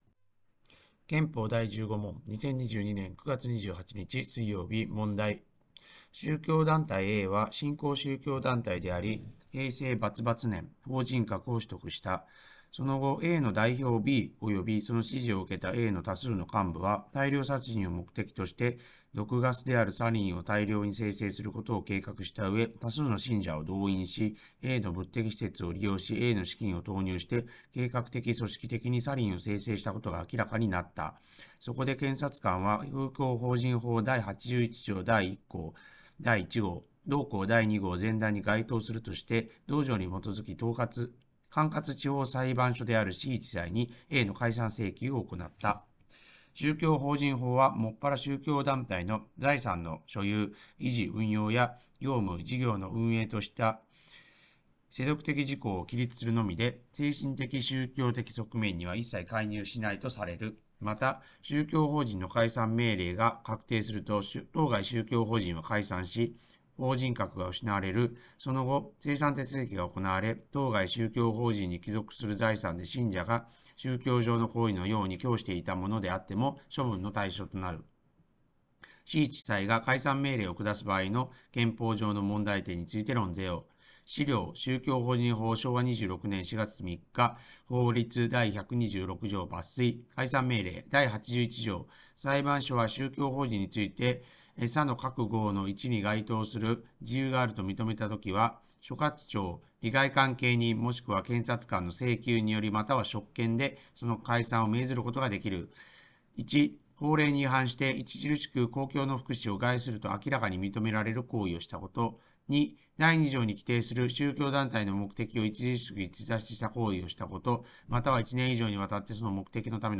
問題解答音読